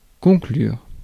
Ääntäminen
IPA: [kɔ̃.klyʁ]